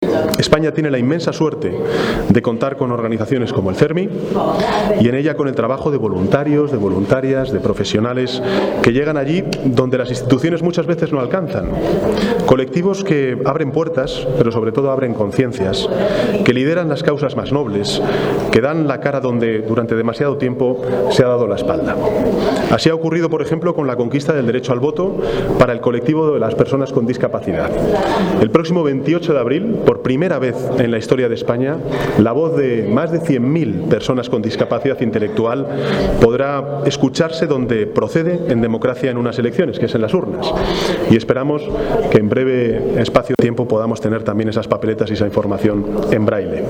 Arriba discurso del presidente, Pedro Sánchez.